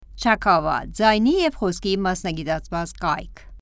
Female
Young
Camercial